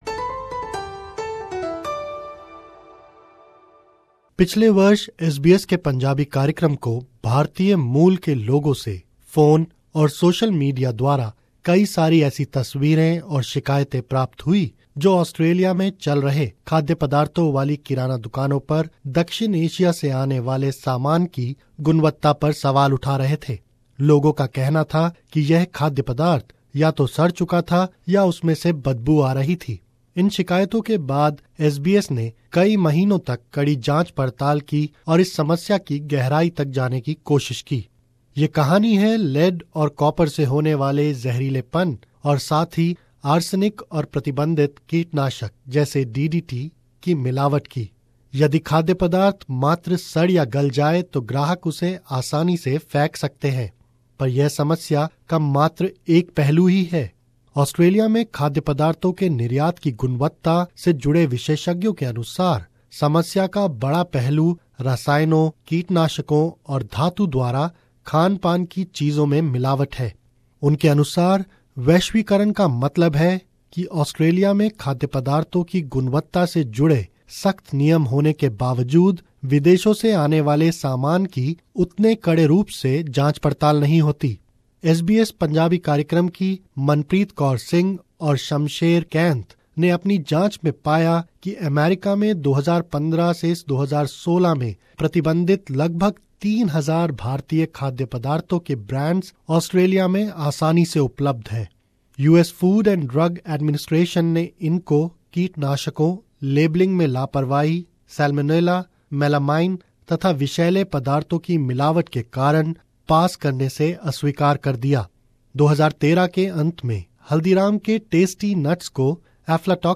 (Sounds of supermarket ... fade under) यदि खाद्य पदार्थ मात्र सड़ या गल जाये तो ग्राहक उसे आसानी से फैंक सकतें हैं.